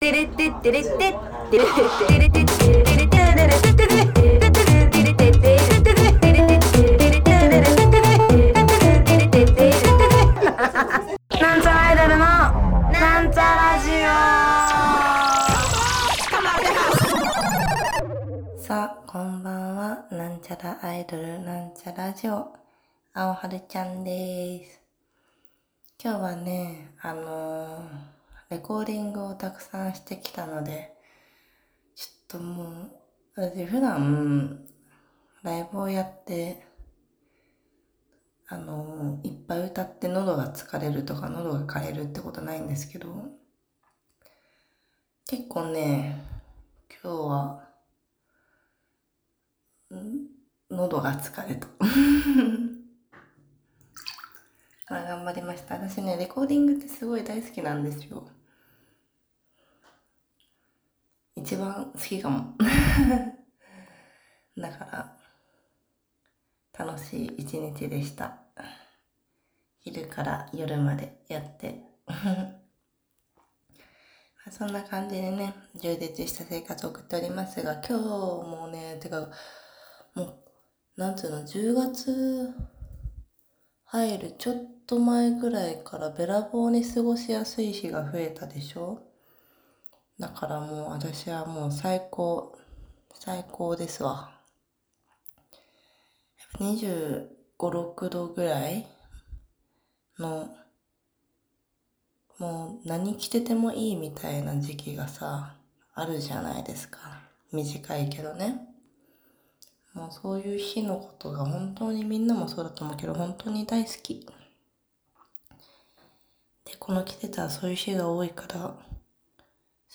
第352回「雑談回」 | なんちゃラジオ